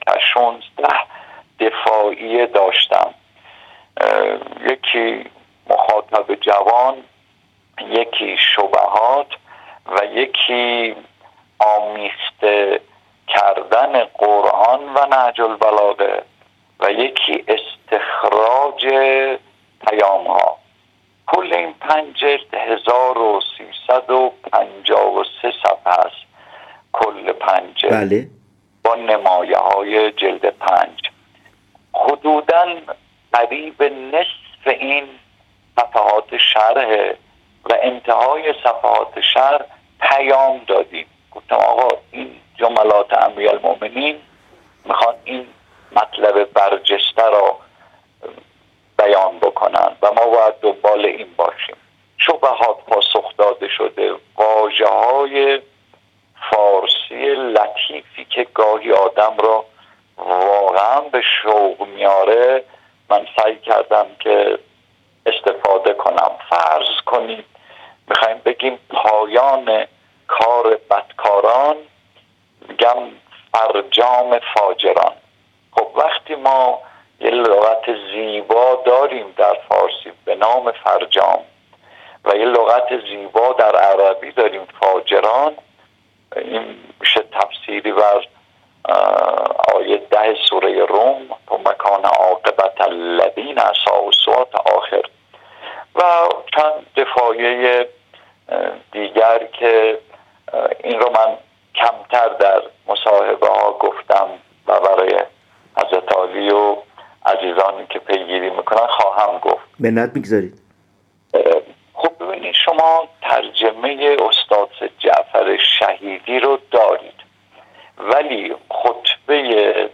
در گفت‌وگو با ایکنا: